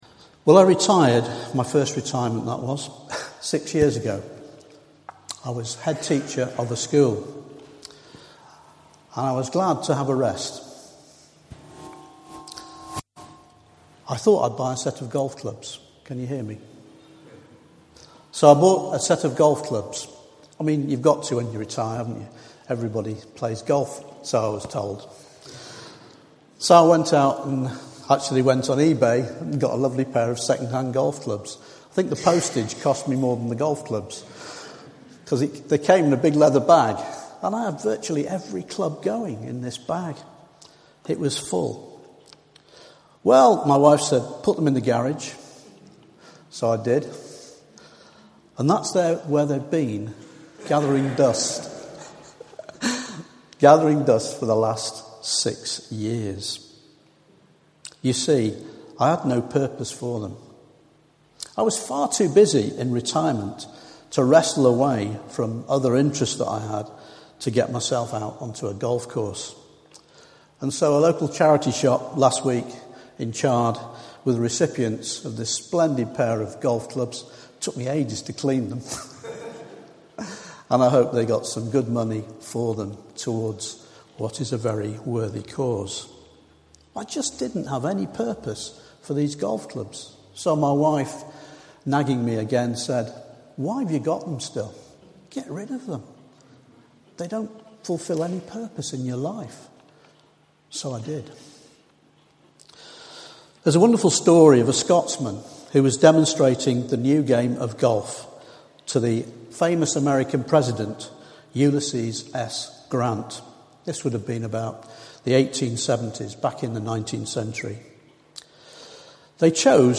Audio file of the 2nd July sermon